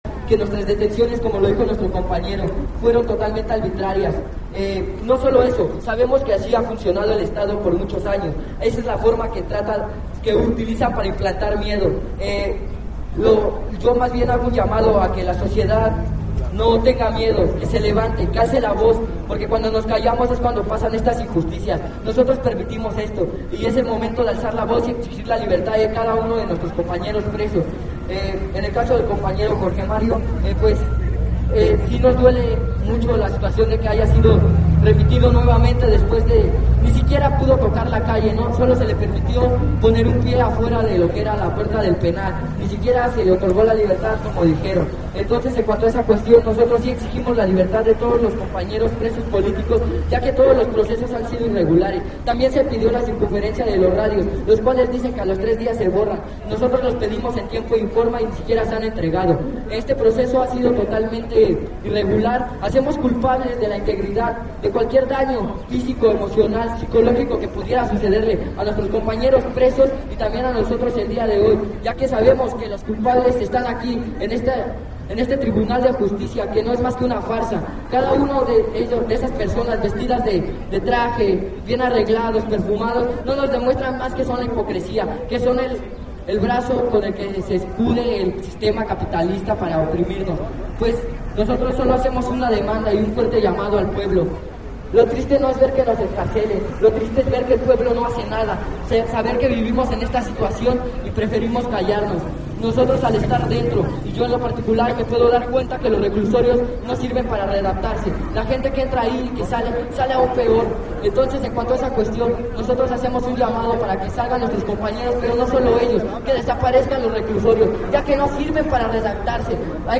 Pasado del medio día, inició el mitin que daría paso a la instalación de las carpas para iniciar la huelga de hambre.